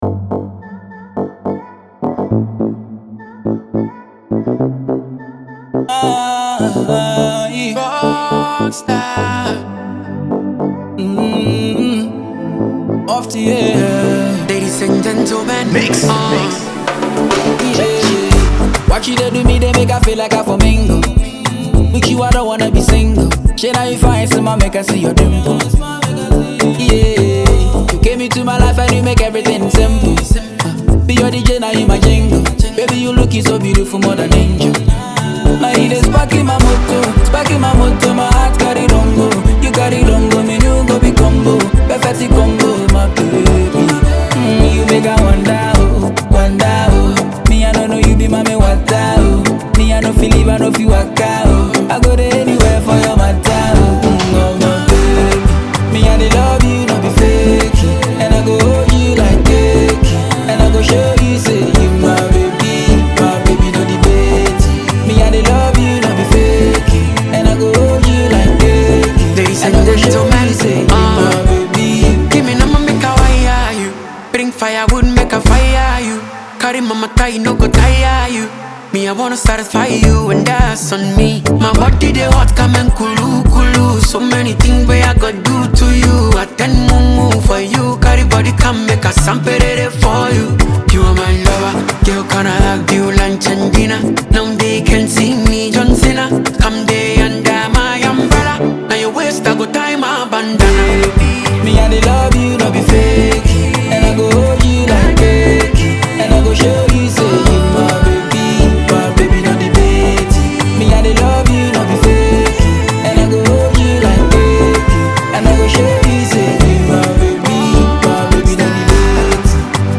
melodic edge
emotional delivery